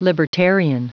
Prononciation du mot libertarian en anglais (fichier audio)
Prononciation du mot : libertarian